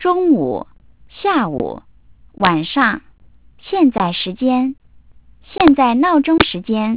- sampling rate : 8 kHz
reconstructed speech